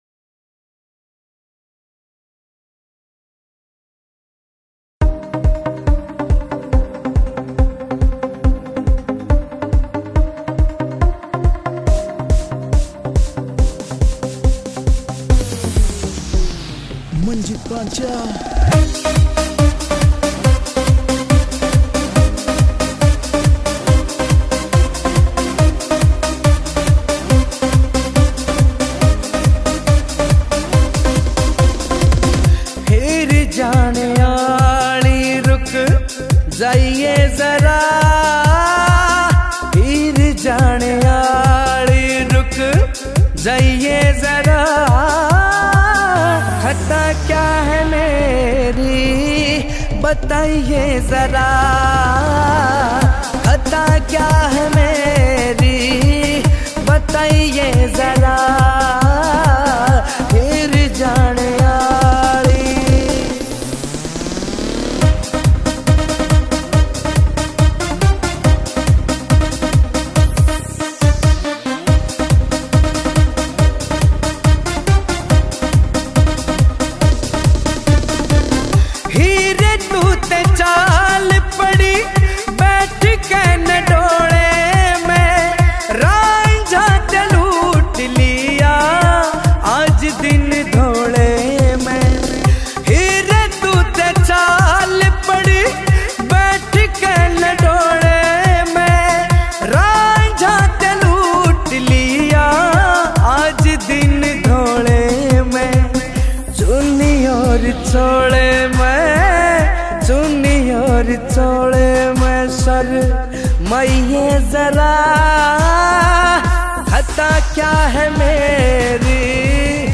Haryanvi Songs